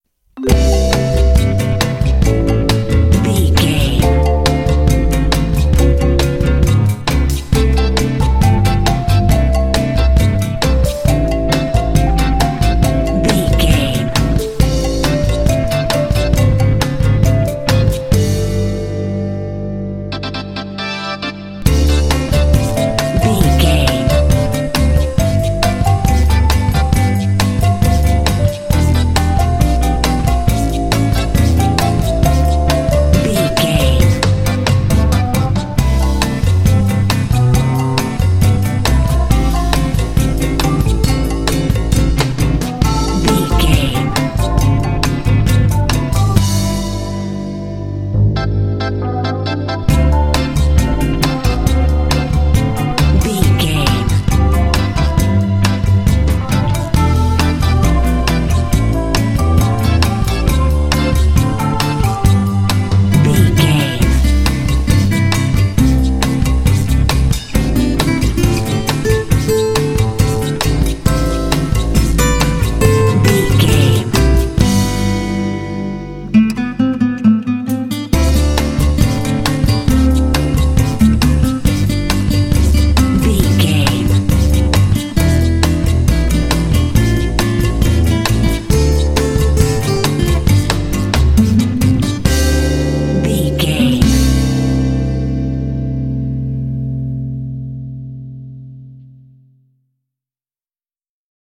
Ionian/Major
light
playful
uplifting
calm
cheerful/happy
electric guitar
bass guitar
percussion
electric organ
electric piano
underscore